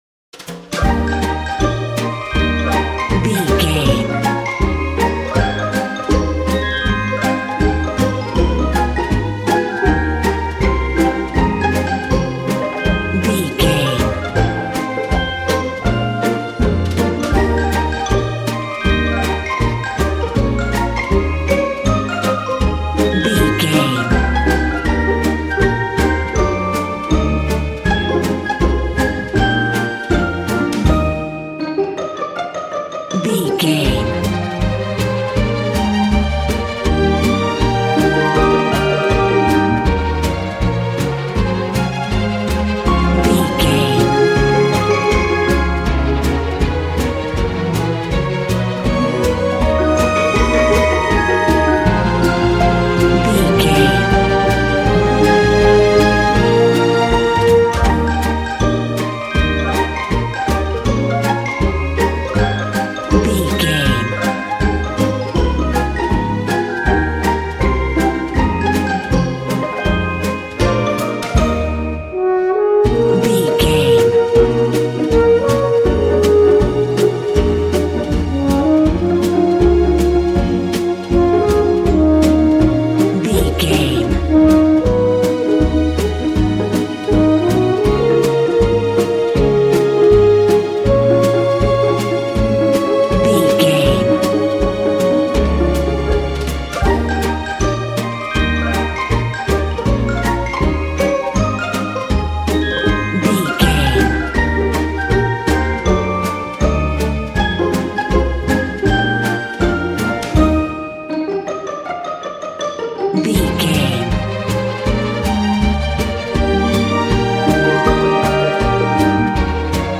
Ionian/Major
D♯
cheerful/happy
playful
frantic